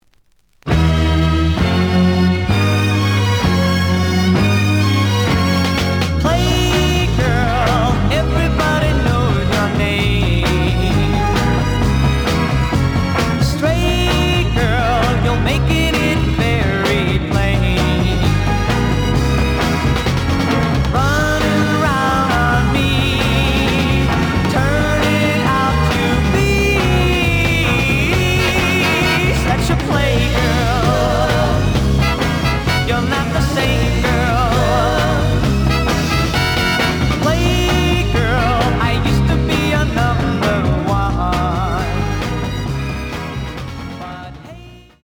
試聴は実際のレコードから録音しています。
●Genre: Rock / Pop
●Record Grading: EX- (B面のラベルに若干のダメージ。多少の傷はあるが、おおむね良好。)